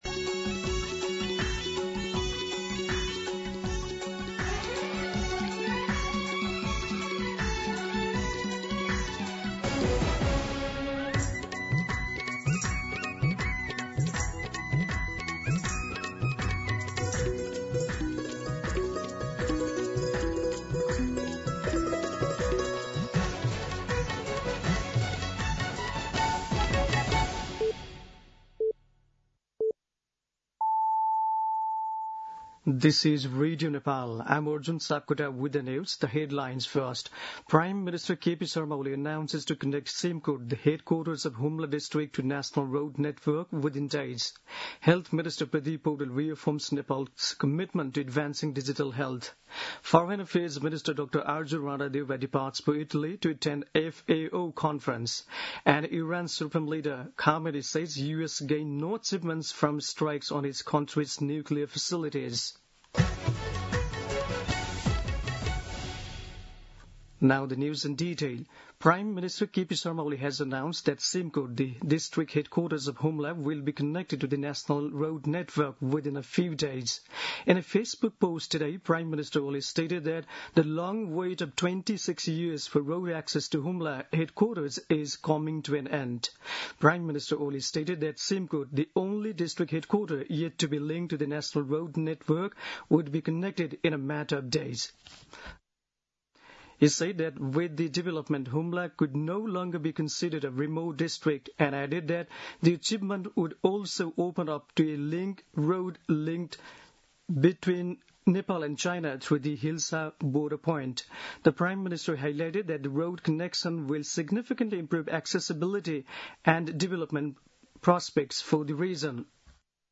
दिउँसो २ बजेको अङ्ग्रेजी समाचार : १३ असार , २०८२
2pm-English-News-13.mp3